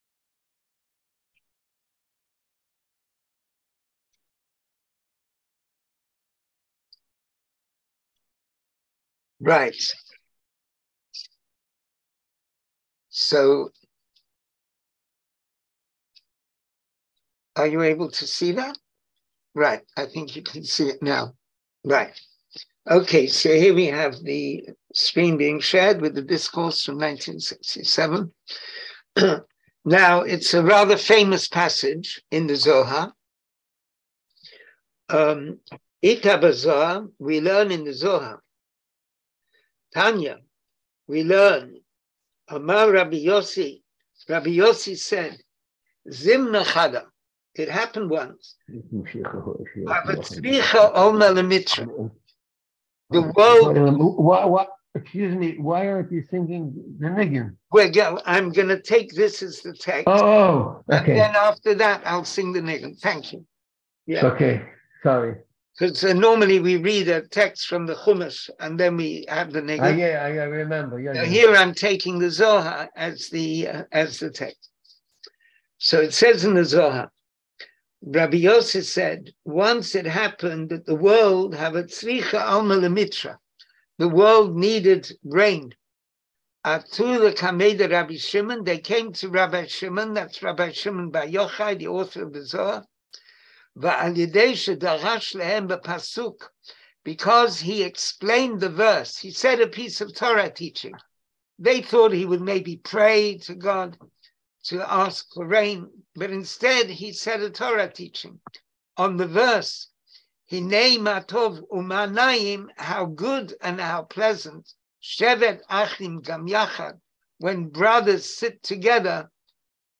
Class audio Listen to the class Watch the Video Class material Hebrew Summary of Discourse English Summary of Discourse Join the class?